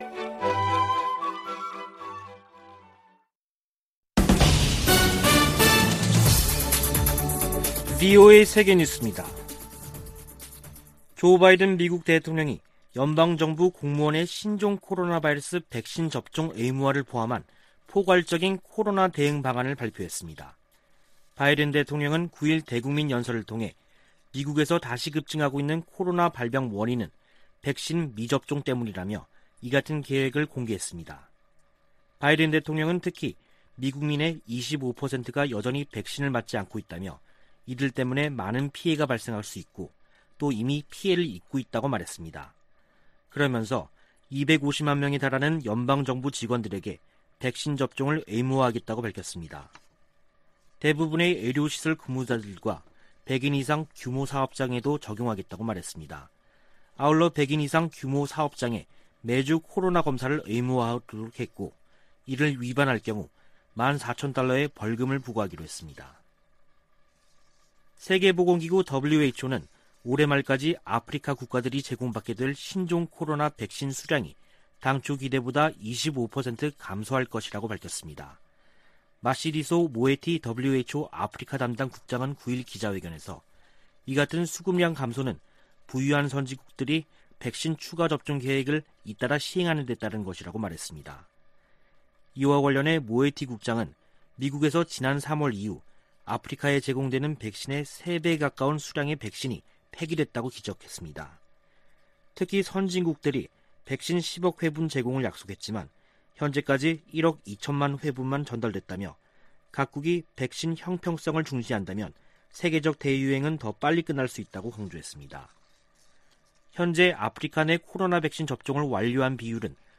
VOA 한국어 간판 뉴스 프로그램 '뉴스 투데이', 2021년 9월 10일 3부 방송입니다. 미국 정부는 한반도의 완전한 비핵화가 여전히 목표이며, 이를 위해 북한과의 외교가 중요하다고 밝혔습니다. 북한의 정권수립 73주년 열병식은 군사 보다는 경제 분야에 집중됐다고 미국의 전문가들이 분석했습니다. 중국과 북한의 강한 신종 코로나바이러스 대응 조치 때문에 탈북 네트워크가 거의 와해 수준인 것으로 알려졌습니다.